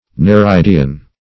Search Result for " nereidian" : The Collaborative International Dictionary of English v.0.48: Nereidian \Ne`re*id"i*an\, n. (Zool.)